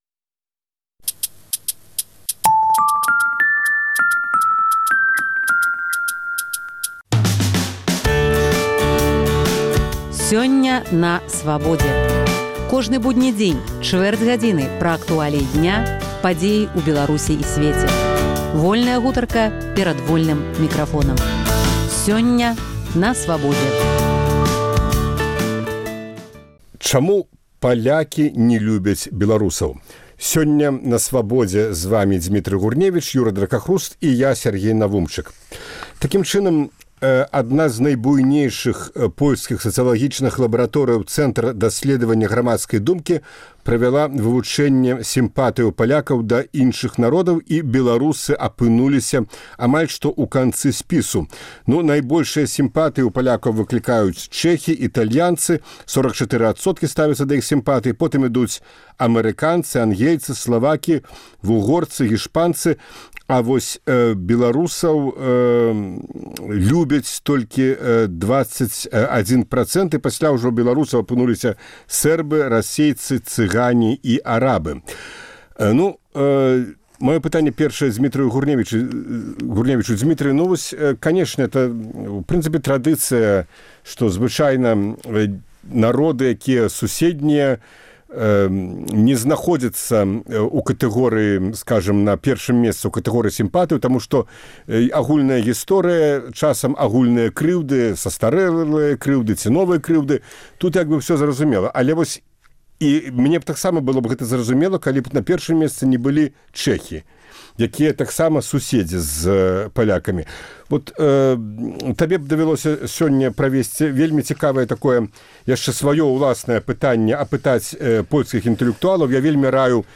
Гутарка на тэмы дня